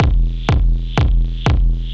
This Is through the Korg Monotron Delay:
First one is original
Dang, that sounds nice!